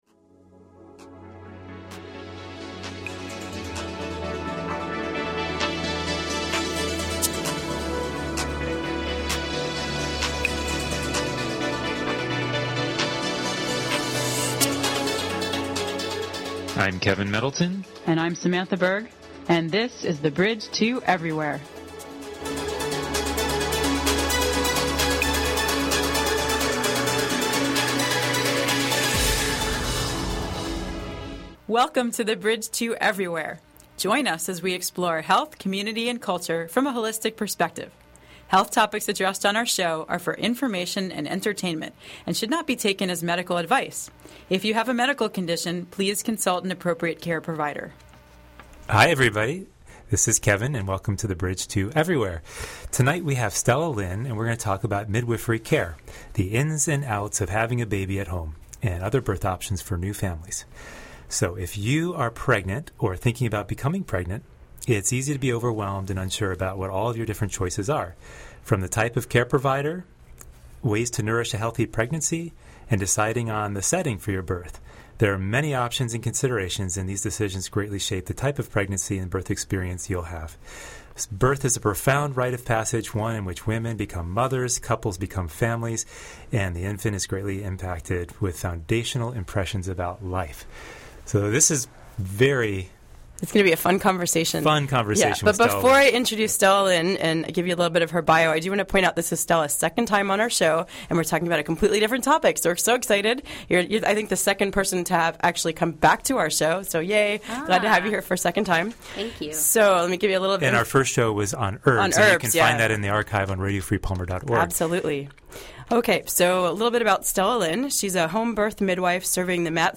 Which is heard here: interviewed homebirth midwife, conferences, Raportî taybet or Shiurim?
interviewed homebirth midwife